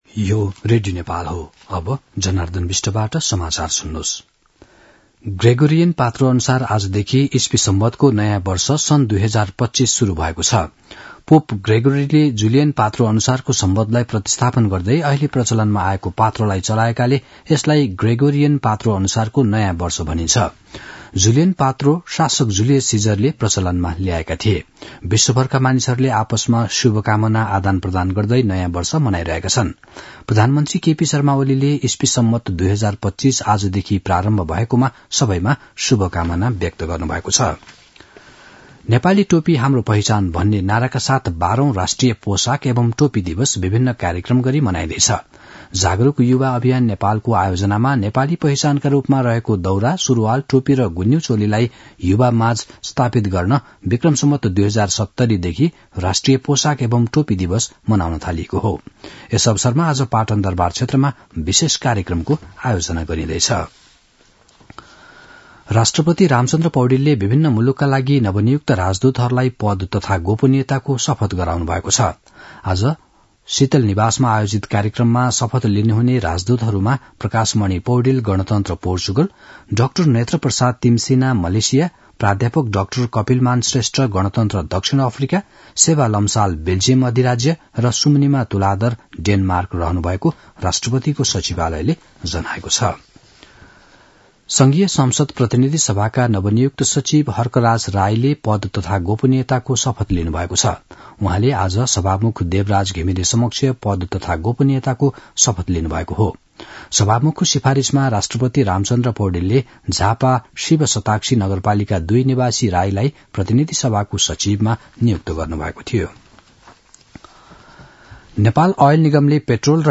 दिउँसो १ बजेको नेपाली समाचार : १८ पुष , २०८१
1-pm-nepali-news-.mp3